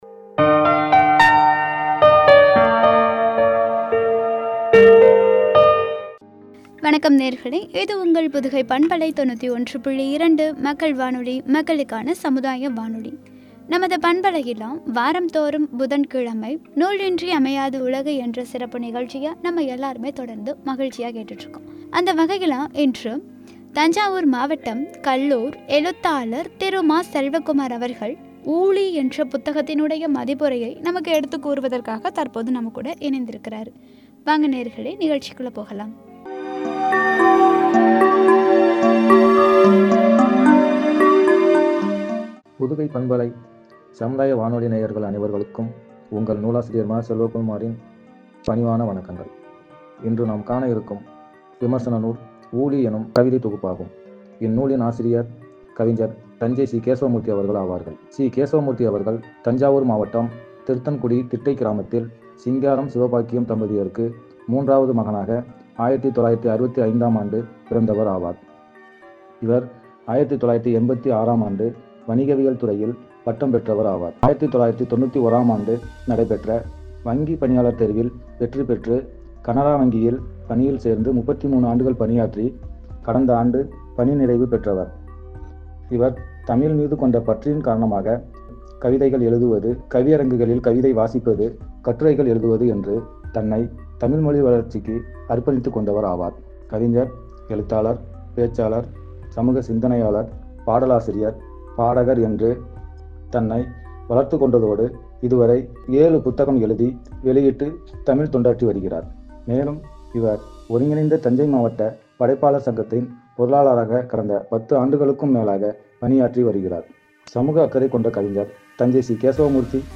“ஊழி”புத்தக மதிப்புரை பகுதி -145 என்ற தலைப்பில் வழங்கிய உரை.